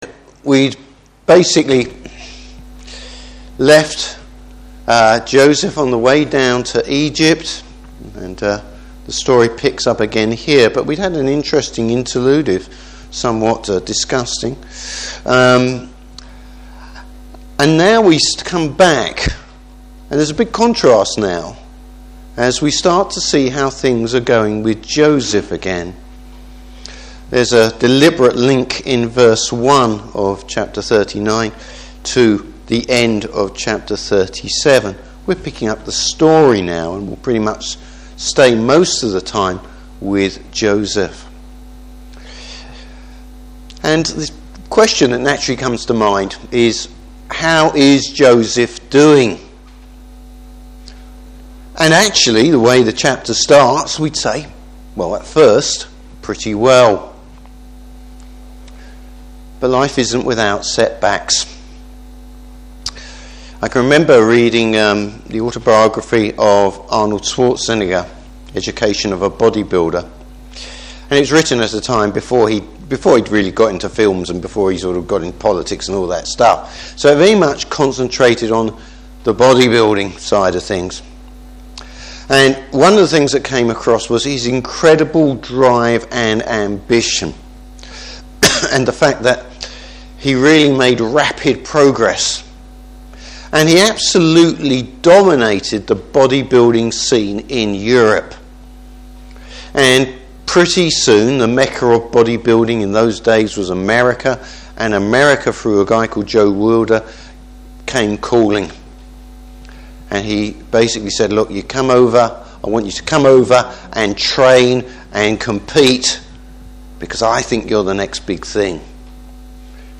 Service Type: Evening Service Is Joseph just unlucky, or is something else going on?